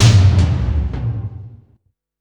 Big Drum Hit 27.wav